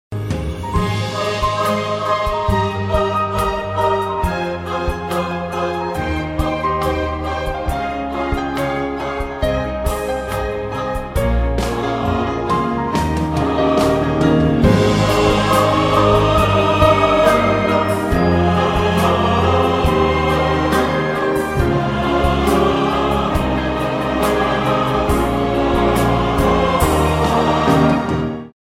I   V   vi   iii   IV   I   IV   V